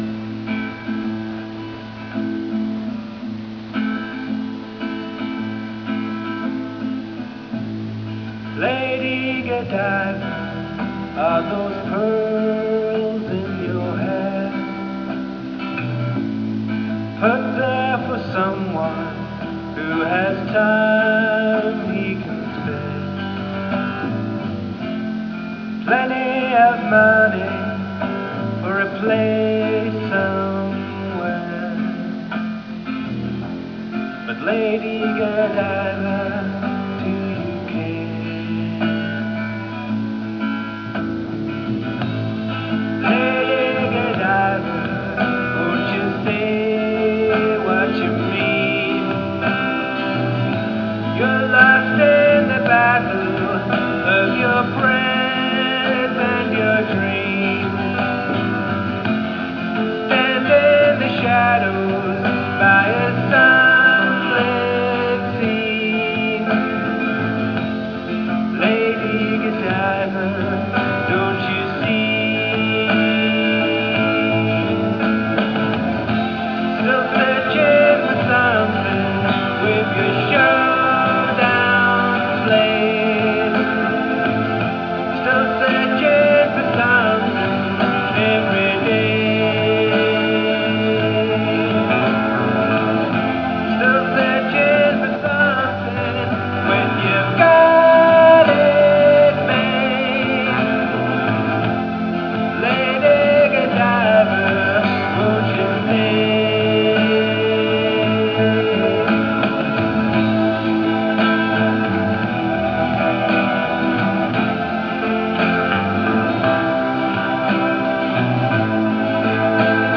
The Cowshed is my recording studio, where I pursue my passion for songwriting and try to make what I've written sound half-decent.
Recorded 8 August 1996 in The Cowshed.